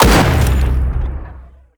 AntiMaterialRifle_1p_03.wav